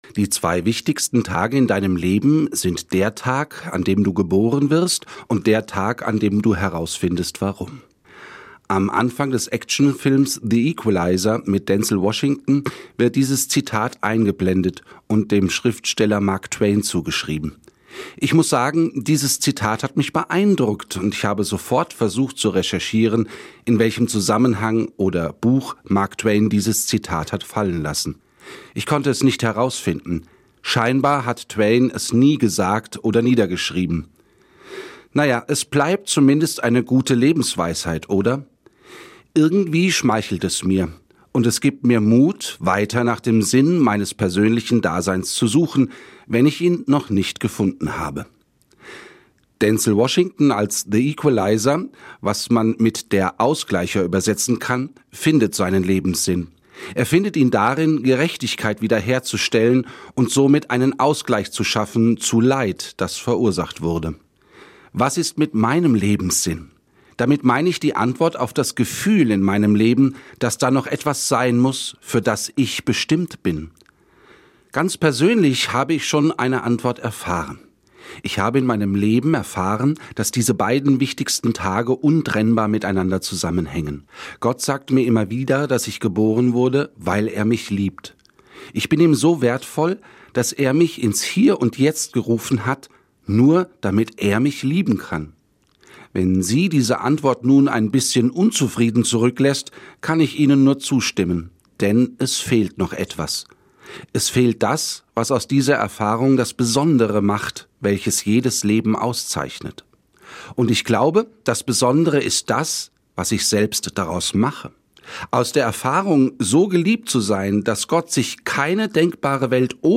Eine Sendung von